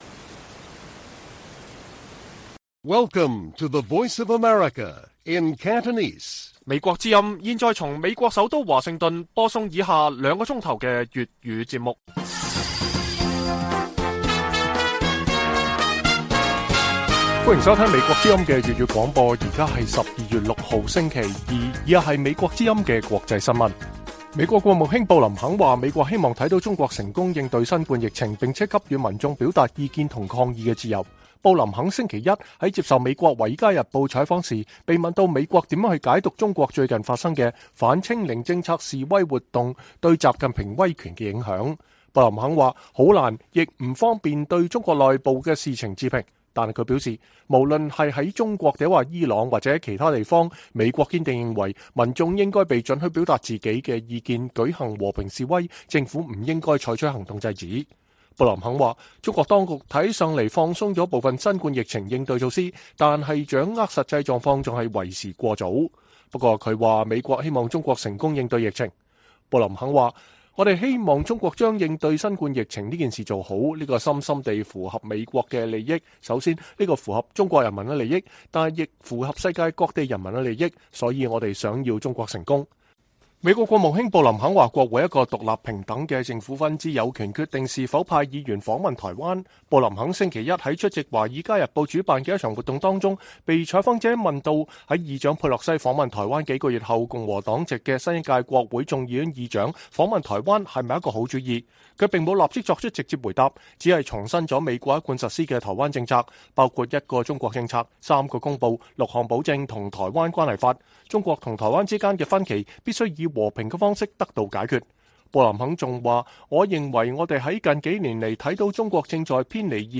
粵語新聞 晚上9-10點: 拜登今日親赴儀式彰顯台積電在美國的“歷史性”投資建廠 有機會與台灣高官互動